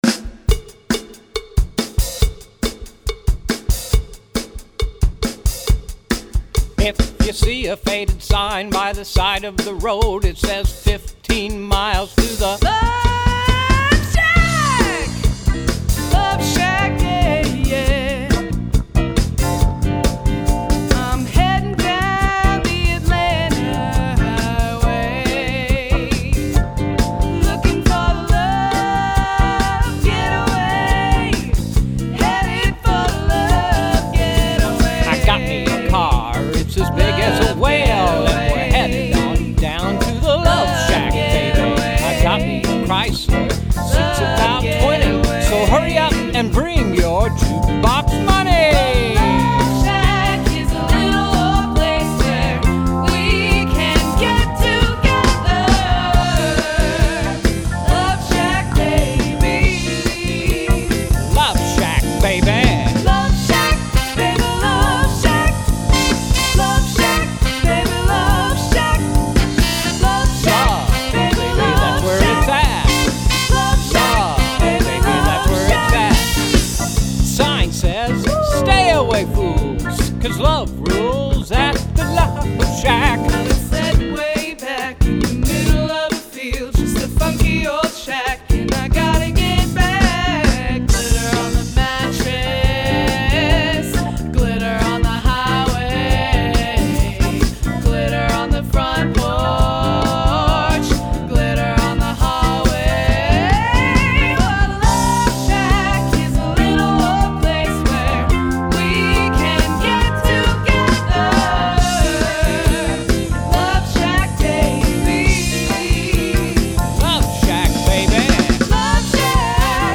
classic party/dance hits